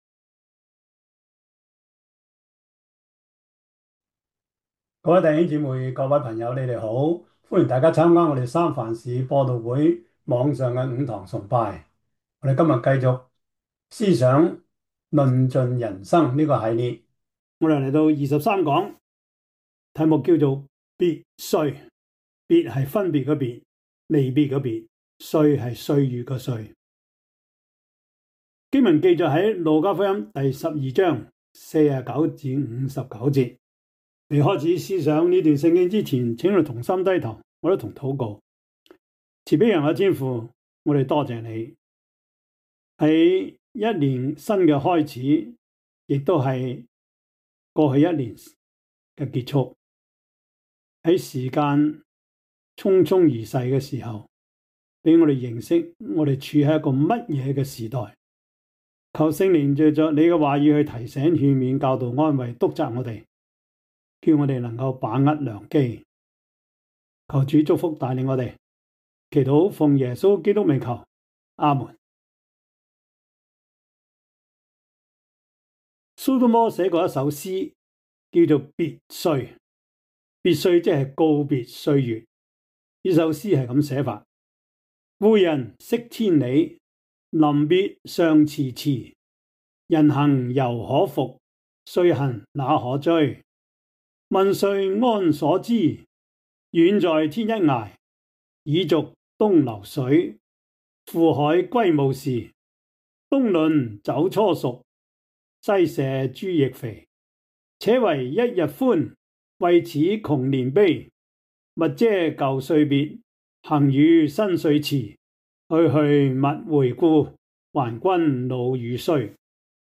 路加福音12:49-59 Service Type: 主日崇拜 路加福音12:49-59 Chinese Union Version